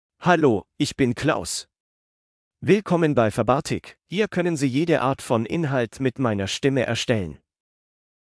MaleGerman (Germany)
KlausMale German AI voice
Klaus is a male AI voice for German (Germany).
Voice sample
Klaus delivers clear pronunciation with authentic Germany German intonation, making your content sound professionally produced.